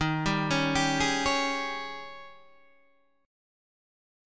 D# 11th